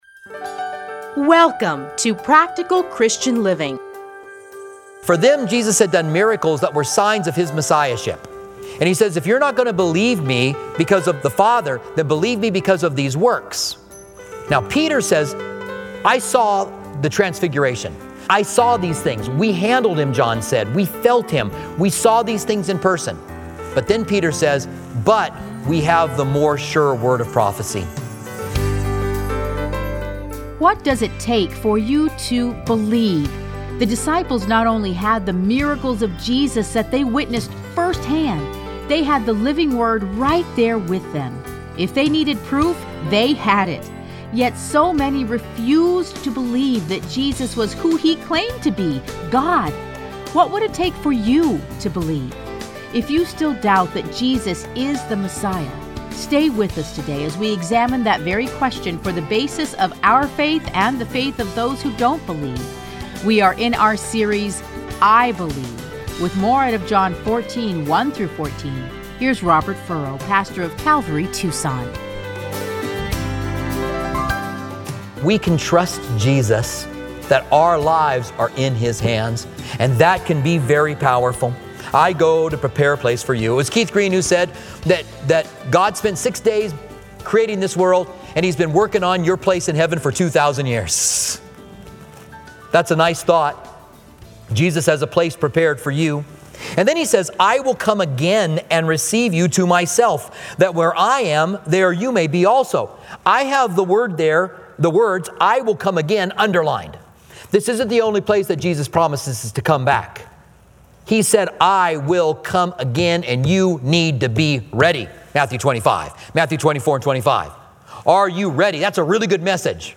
Listen to a teaching from John 14:1-14.